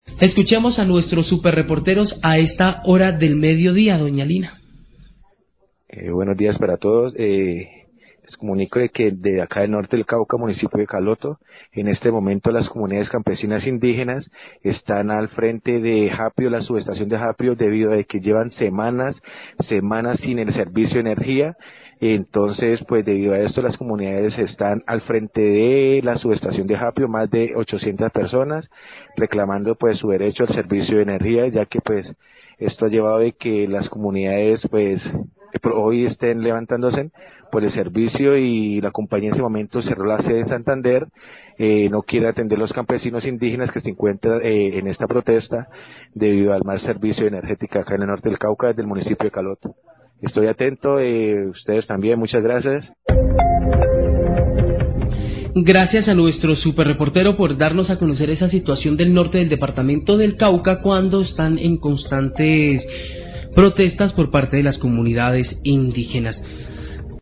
Oyente a través del whatsapp de la emisora envia mensaje informando sobre el plantón frente a la subestación japio que realizan la comunidad campesina e indígenas de Caloto en protesta por deficiencias en el servicio de energía.